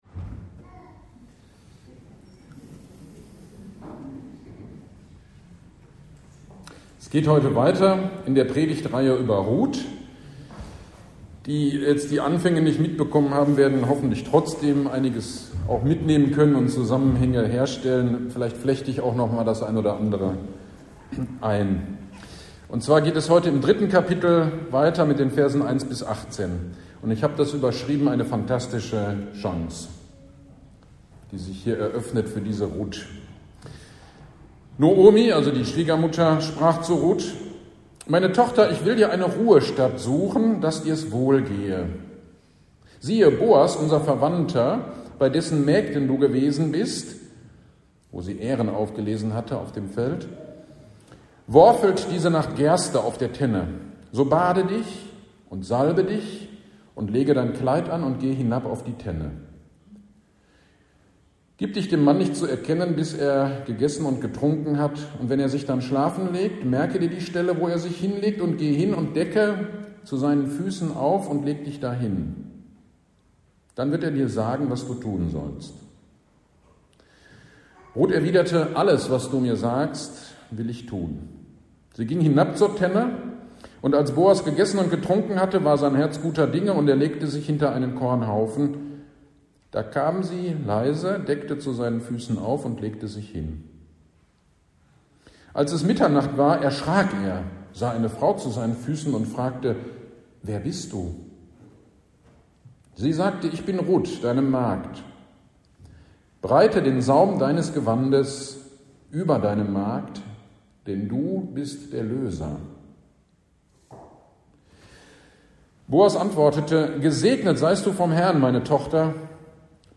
GD am 11.06.23 Predigt zu Rut 3.1-18